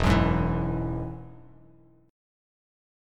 Eadd9 chord